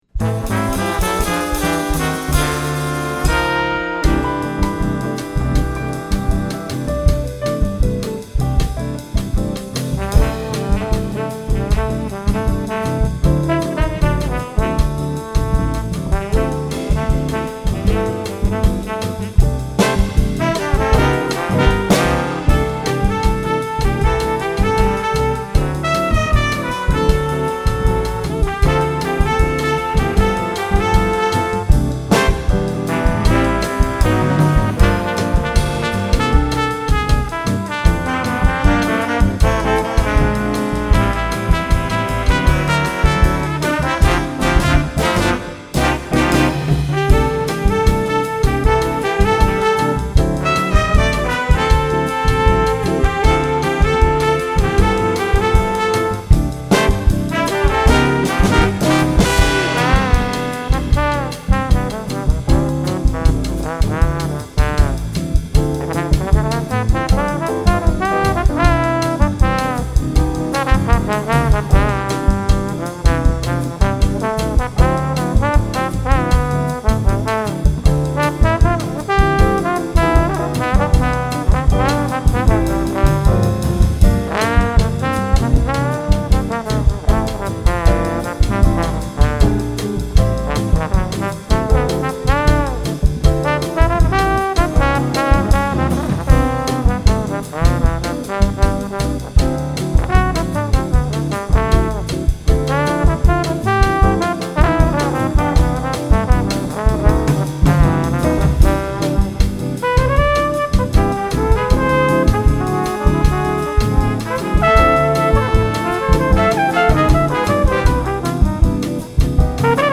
Voicing: Jazz Combo